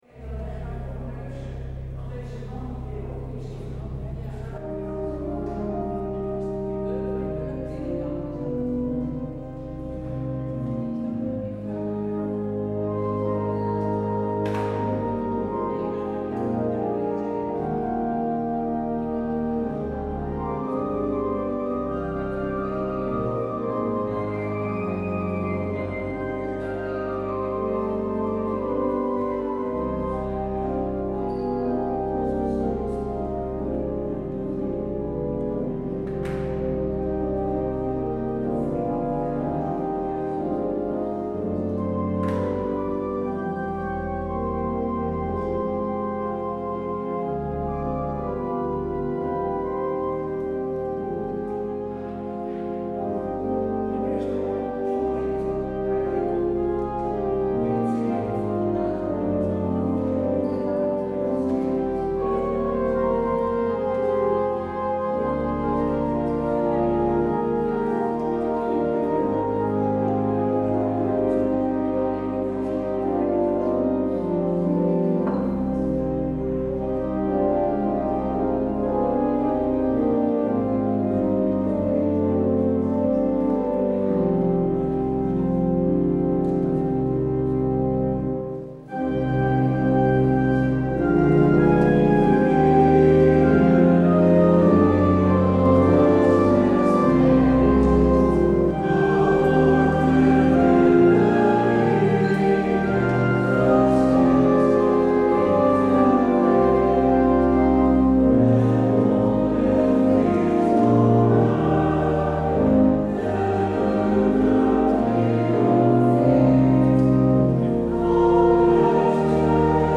 Luister deze kerkdienst hier terug: Alle-Dag-Kerk 7 mei 2024 Alle-Dag-Kerk https